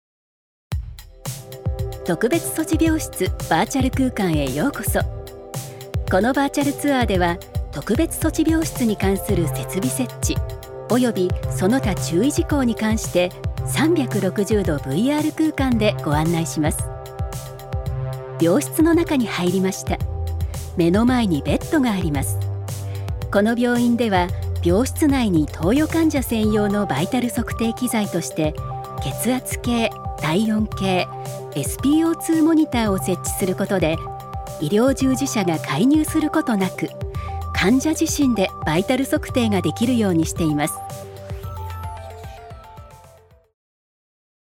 女性タレント
ナレーション５